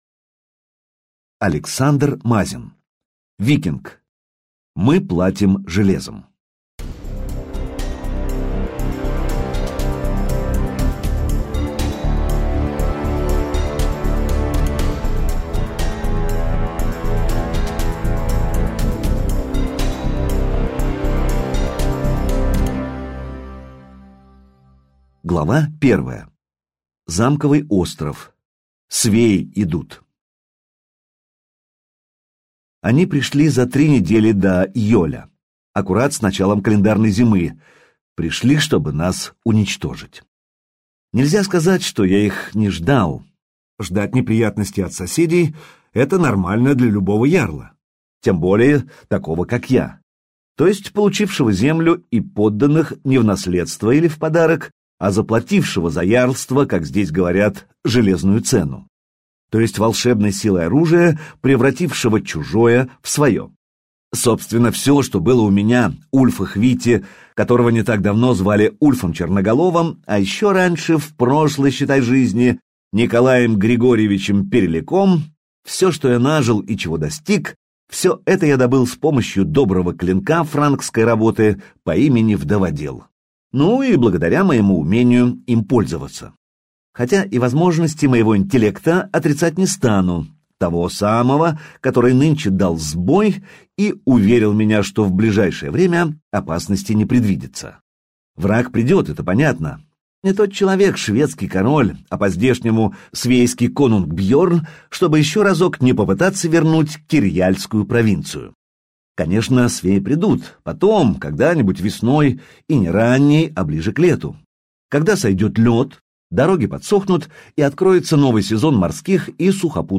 Аудиокнига Мы платим железом - купить, скачать и слушать онлайн | КнигоПоиск